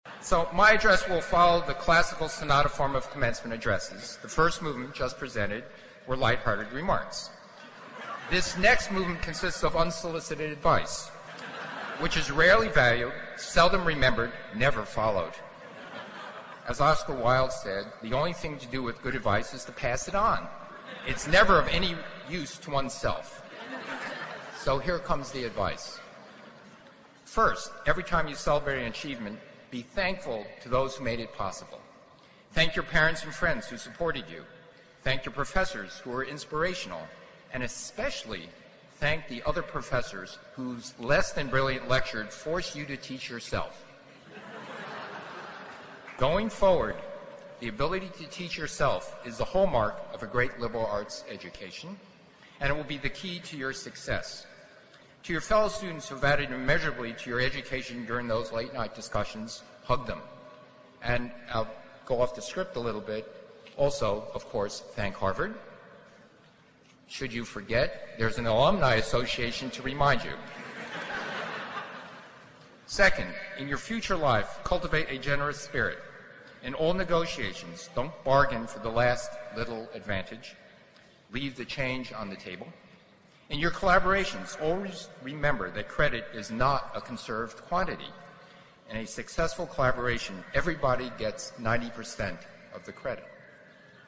名校励志英语演讲 03:给大学毕业生的几个忠告 听力文件下载—在线英语听力室
借音频听演讲，感受现场的气氛，聆听名人之声，感悟世界级人物送给大学毕业生的成功忠告。